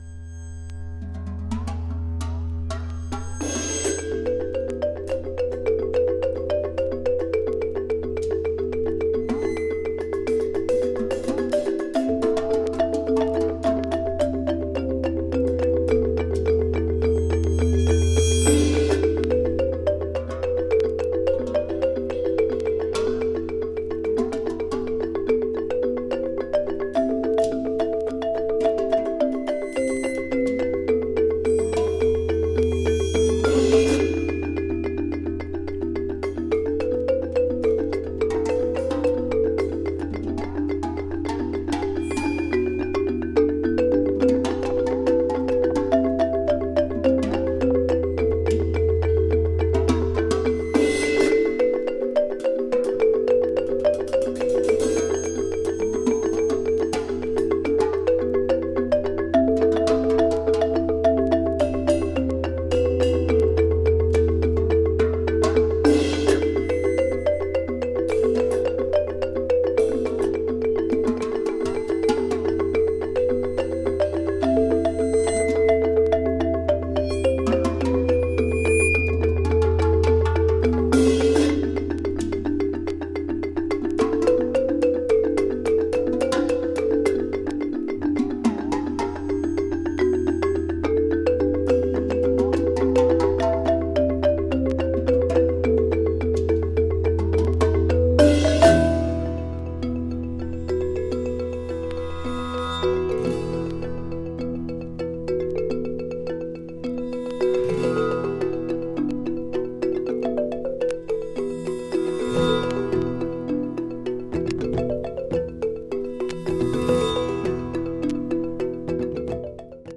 心地良い打楽器サウンドが盛りだくさん。'
盤もキレイですが、若干チリ・パチノイズ入る箇所あります。
Brazil Experimental